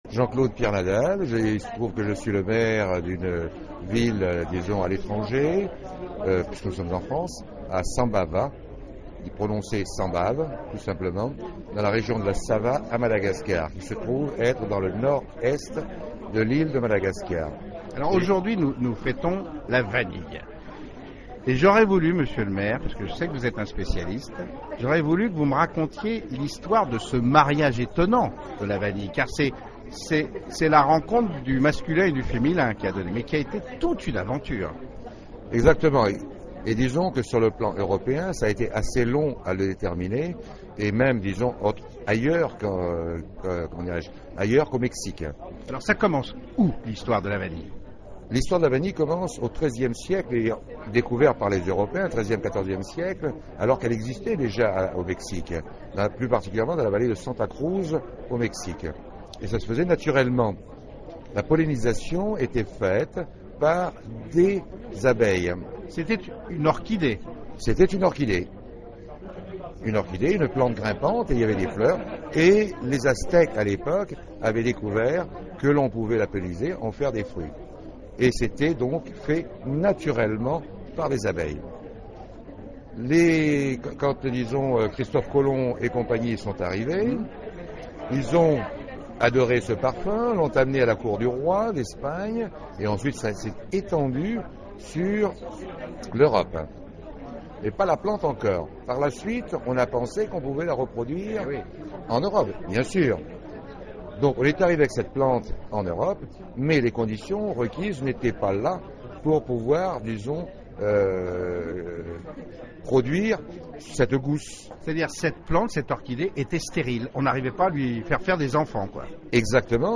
C’est le maire de Sambava, une ville du nord-est de Madagascar réputée pour sa vanille Bourbon.